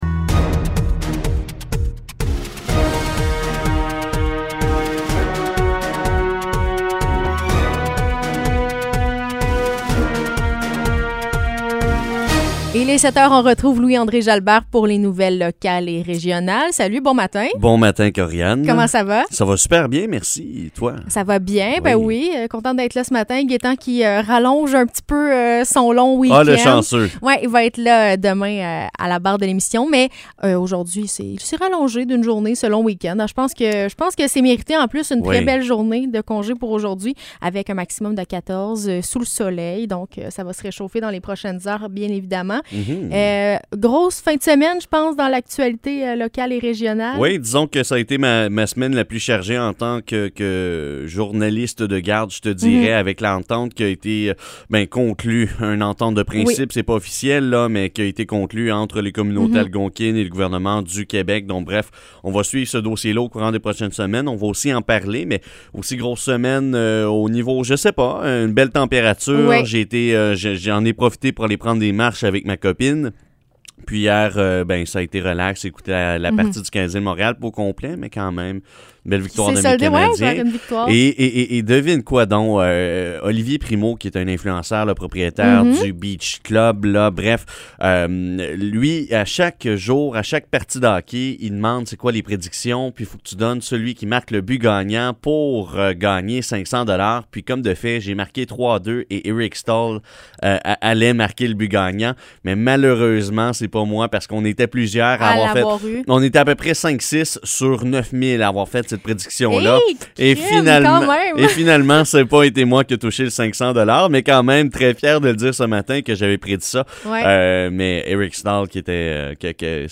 Nouvelles locales - 6 Avril 2021 - 7 h